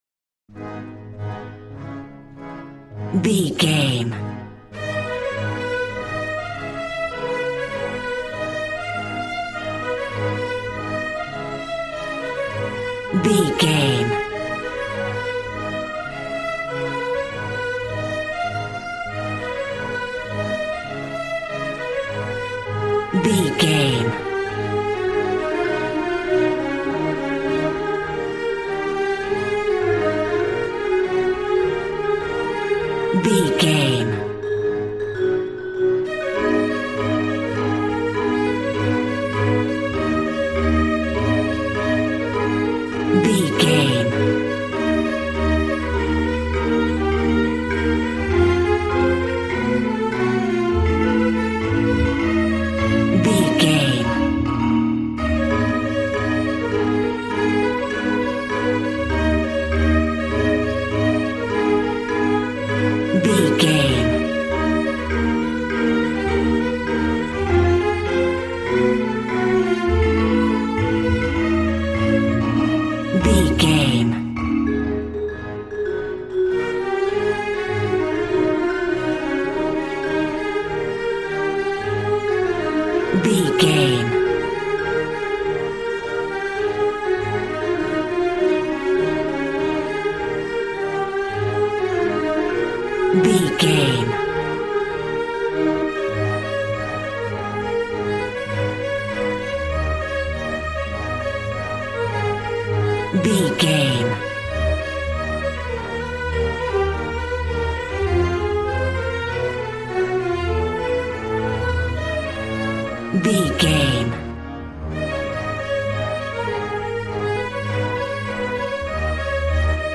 Aeolian/Minor
A♭
positive
cheerful/happy
joyful
drums
acoustic guitar